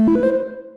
Techmino/media/effect/chiptune/spin_1.ogg at 65c050f6df9867f19b509d00a08efd7ffad63b17
spin_1.ogg